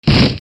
石壁に身体を打ち付ける 01
/ H｜バトル・武器・破壊 / H-90 ｜その他材質